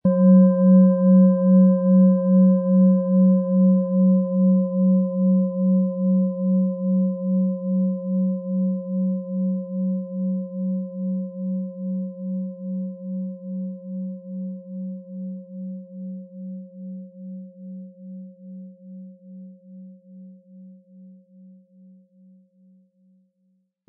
• Tiefster Ton: Tageston
Im Audio-Player - Jetzt reinhören hören Sie genau den Original-Ton der angebotenen Schale.
Spielen Sie die OM-Ton mit dem beigelegten Klöppel sanft an, sie wird es Ihnen mit wohltuenden Klängen danken.
MaterialBronze